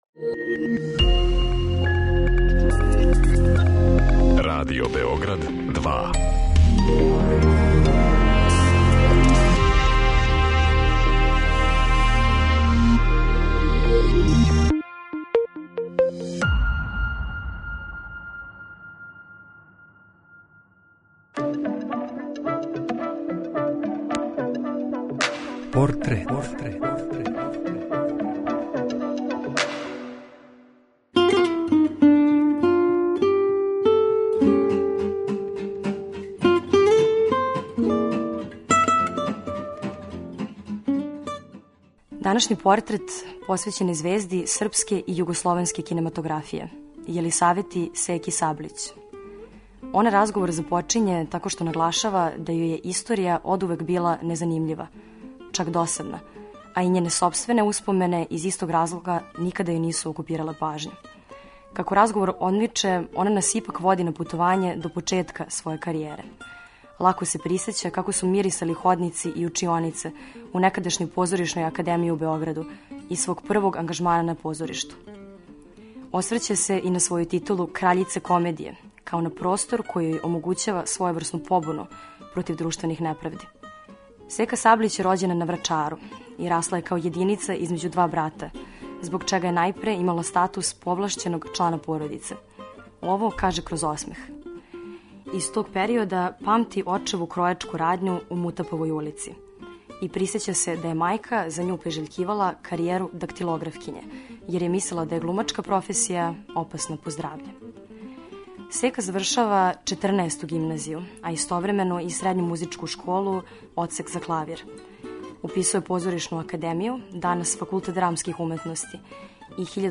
суптилним радиофонским ткањем сачињеним од: интервјуа, изјава, анкета и документраног материјала.